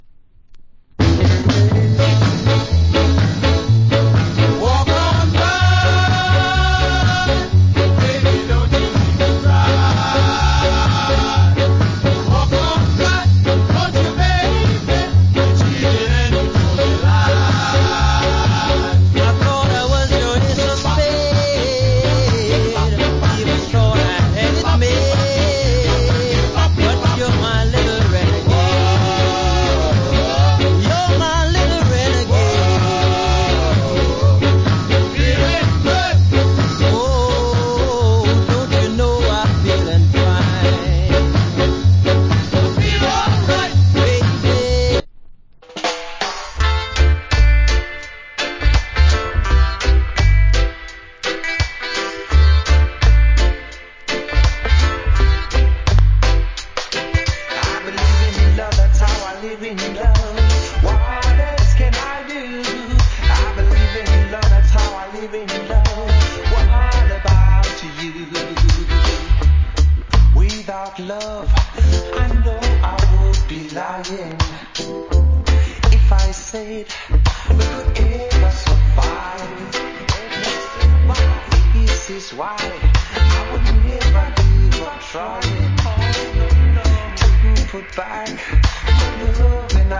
Killer Ska Vocal.